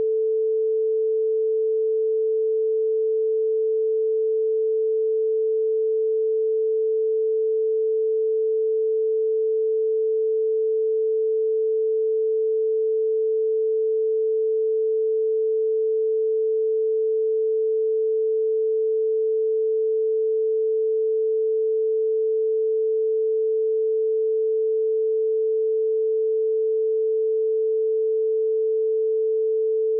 Um momento de calma, por conta da casa.
Áudio de respiração guiada que gravei pra você.
Respiração Guiada